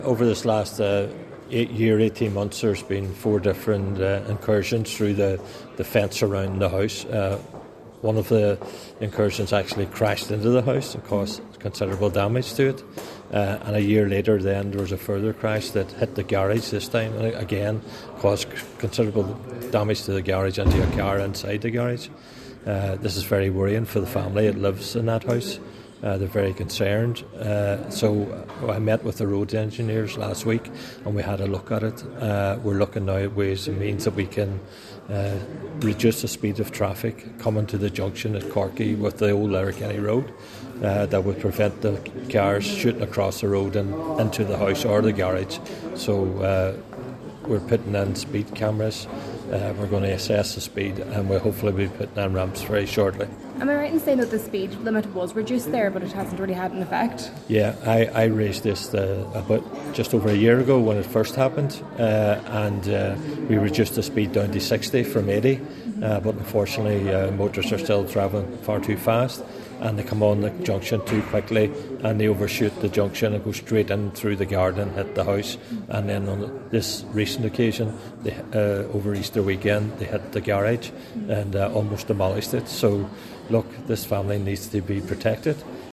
Cllr McMonagle says the speed limit has been reduced there before, however, to no avail: